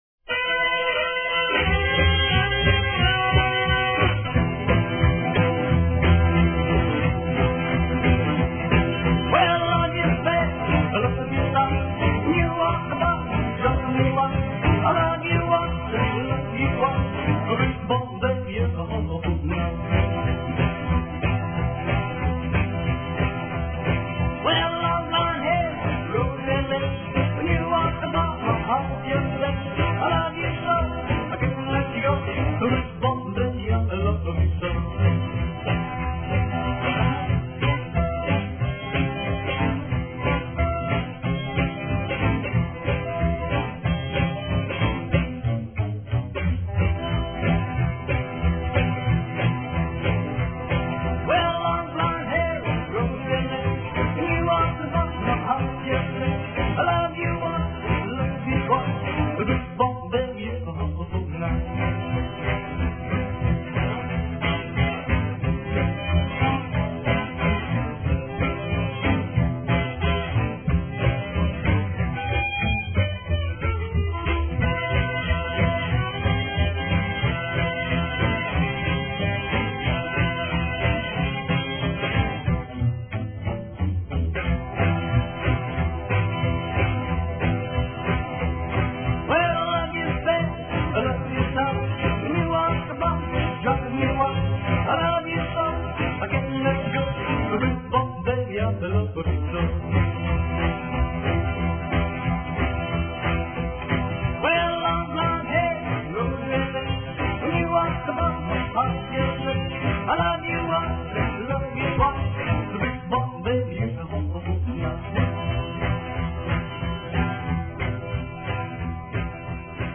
pure, undiluted rockabilly